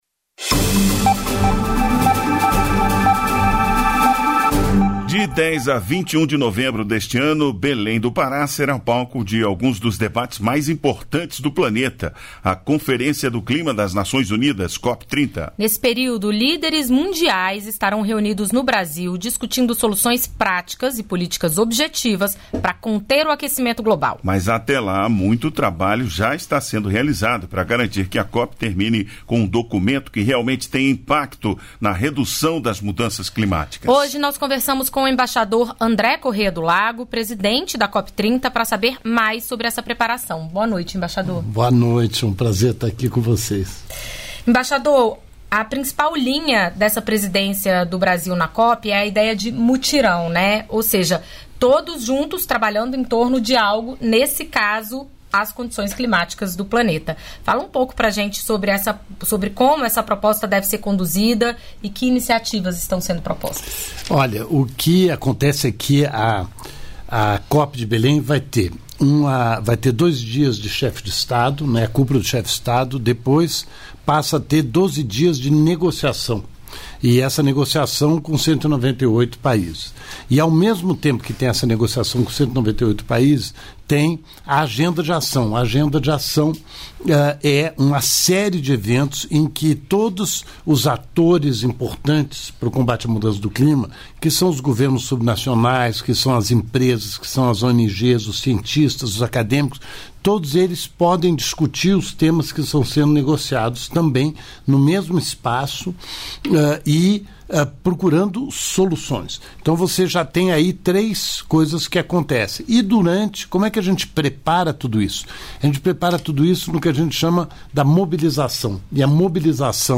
Entrevistas da Voz